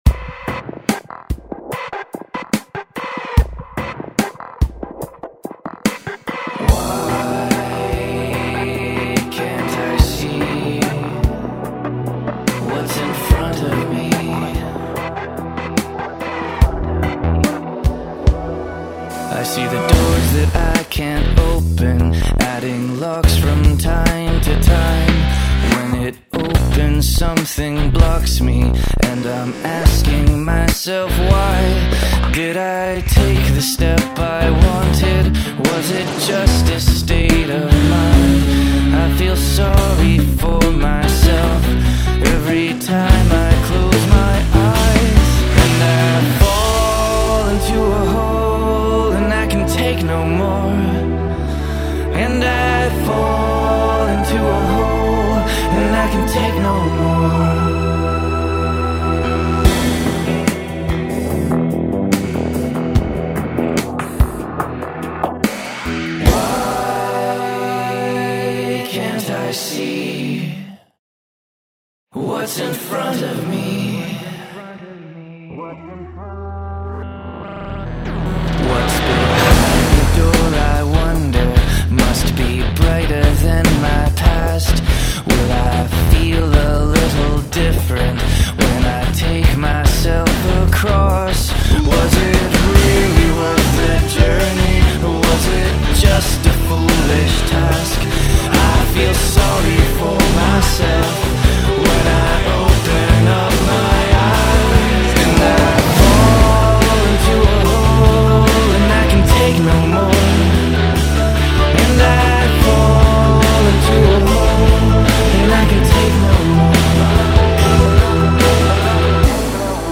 BPM73
Audio QualityMusic Cut